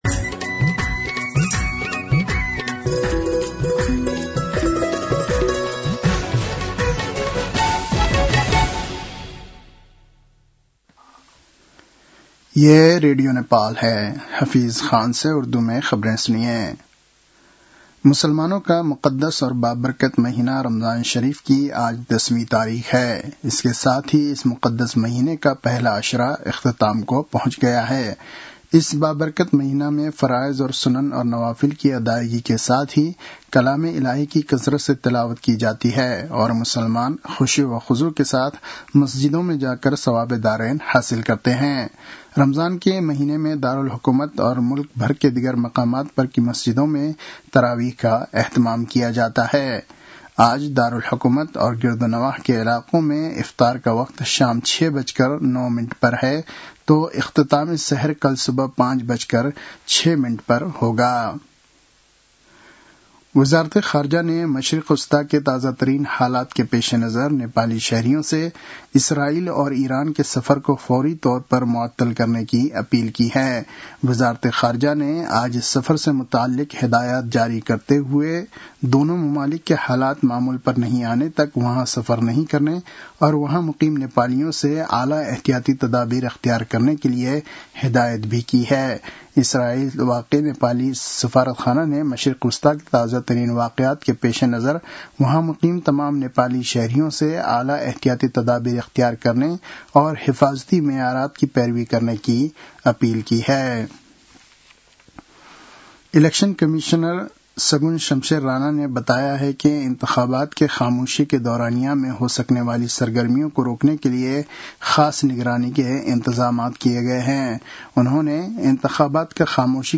An online outlet of Nepal's national radio broadcaster
उर्दु भाषामा समाचार : १६ फागुन , २०८२